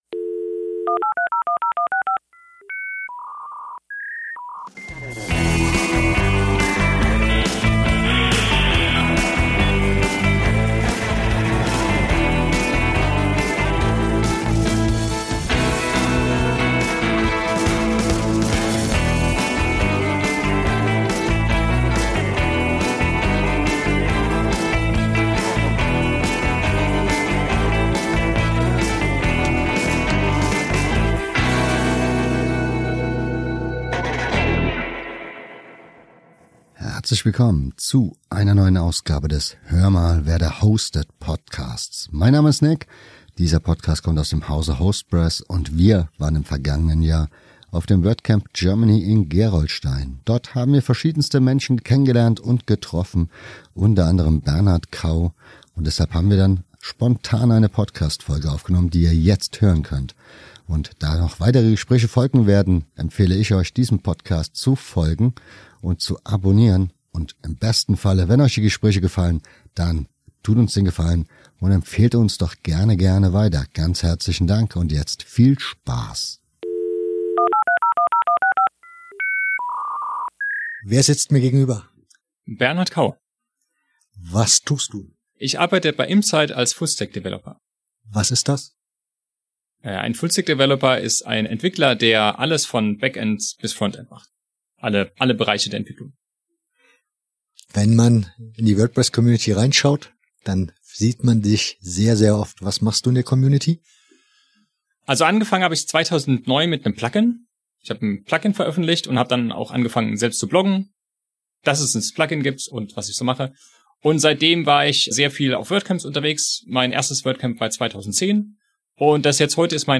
Wir waren im vergangenen Jahr auf dem WordCamp Germany in Gerolstein und haben da zahlreiche Gespräche geführt und teils aufgenommen.